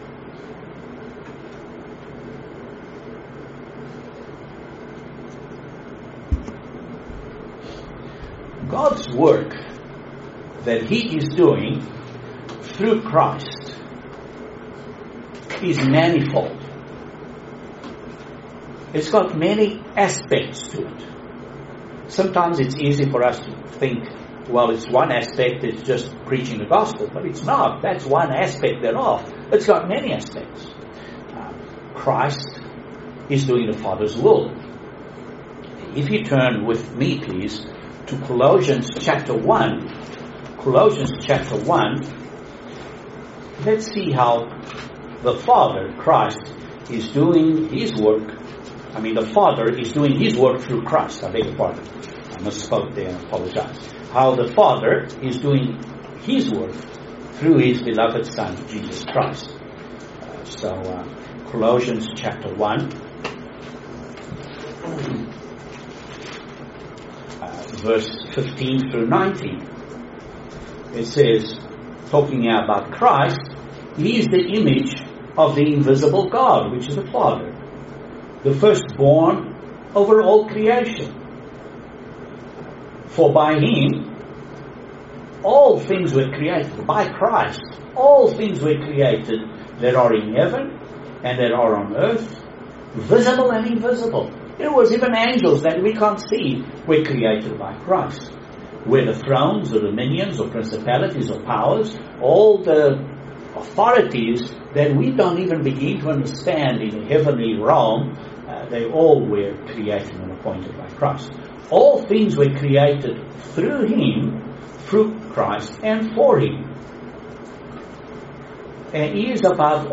Excellent sermon on how the physical offerings of ancient Israel pointed to Jesus Christ.